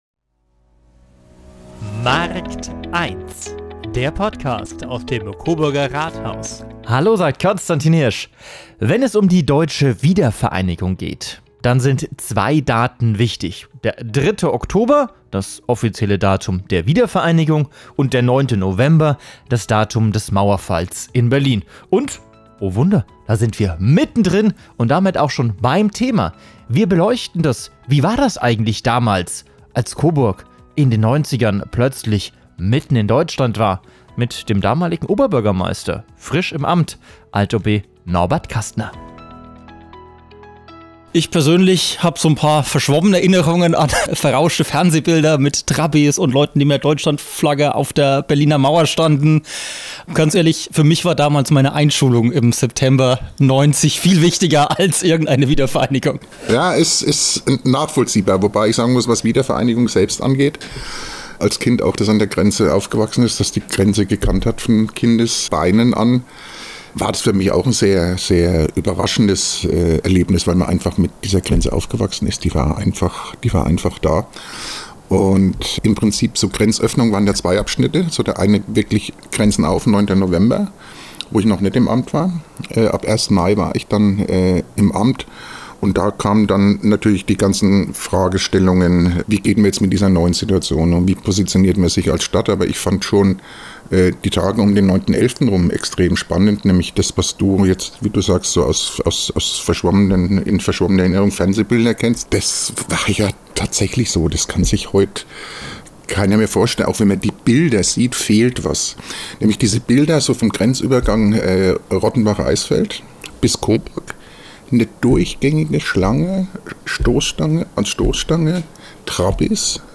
35 Jahre Mauerfall, 33 Jahre Wiedervereinigung – Coburg als Grenzstadt hat die Wendezeit hautnah erlebt. In dieser Folge sprechen wir mit dem damaligen Oberbürgermeister Norbert Kastner über die Herausforderungen, Chancen und besonderen Momente der frühen 90er-Jahre.